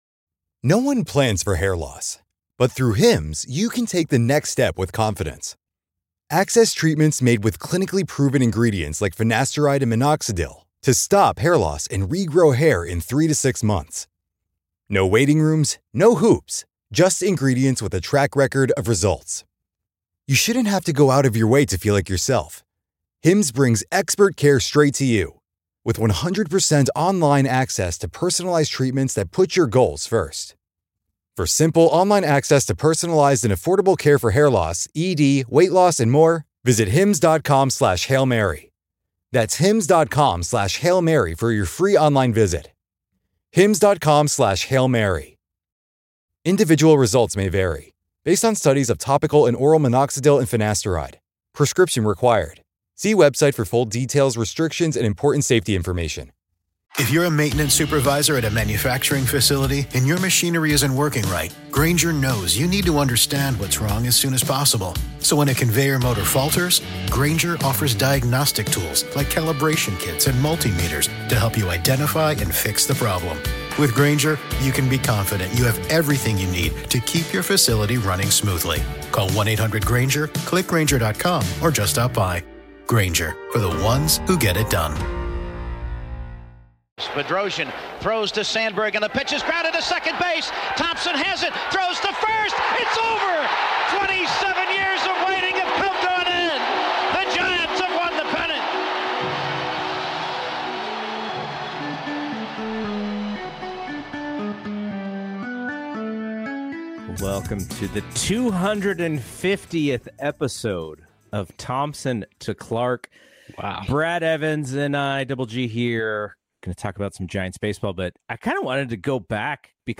The guys recorded this episode in the final hour of the deadline and react in real time to some of the biggest deals of the day.